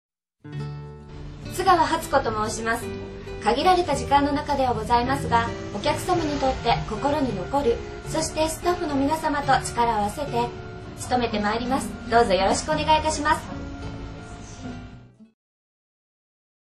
優しく落ち着いた声質。 穏やかで温かい雰囲気でお客様に人気があります。